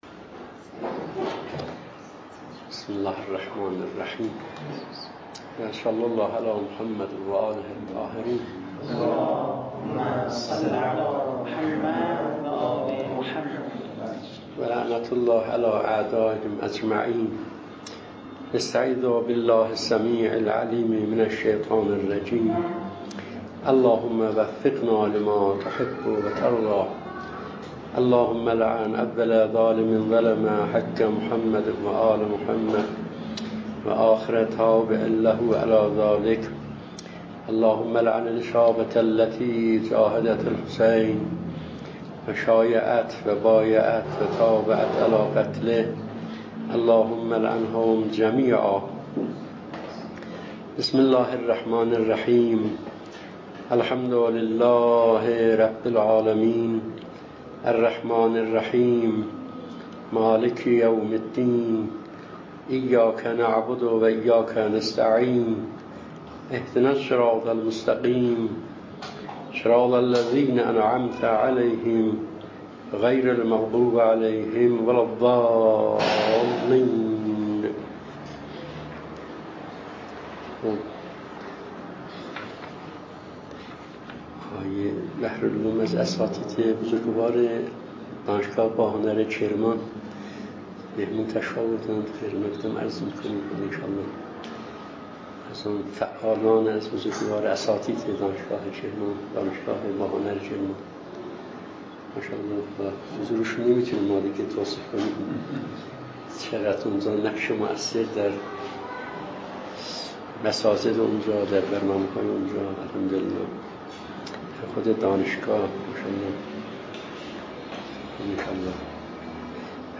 🔰موضوع: پرسش و پاسخ های تربیتی 👥مخاطب: اساتید دانشگاه 📅زمان: 1398/6/4 🌎مکان: دانشگاه تبریز اهمّ سؤالات: ▪علت تفاوت سبک تربیتی اساتید اخلاق چیست؟